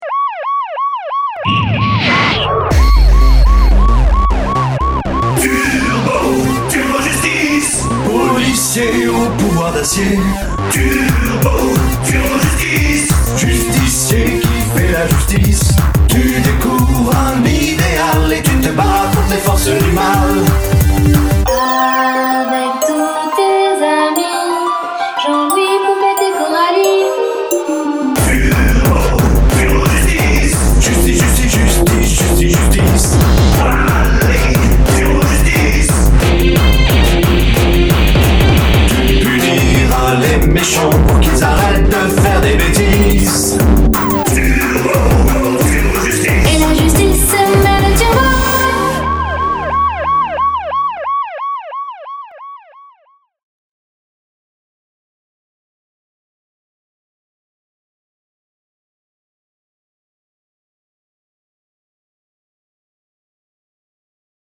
Voila déjà la chanson en mp3 :
a oui, et le SFX à la fin, ça serait pas pecho de street of rage par hasard ?